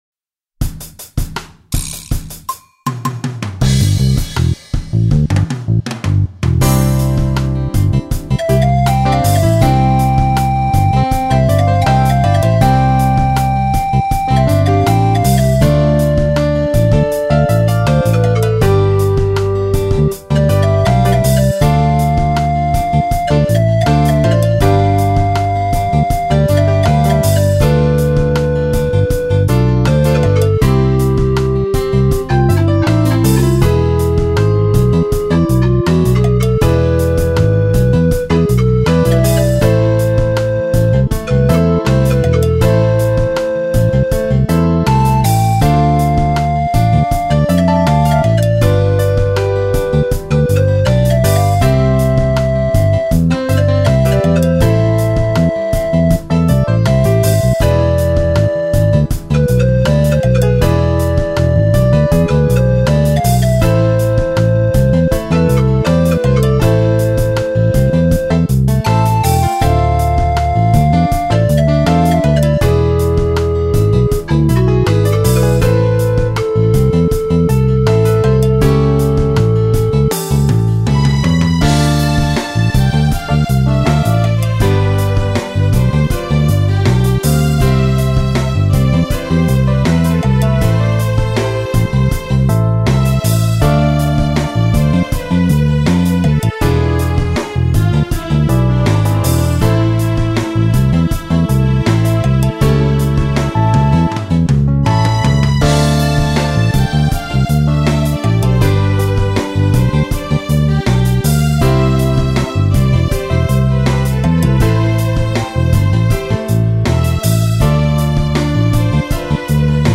2980   03:33:00   Faixa: 7    Clássica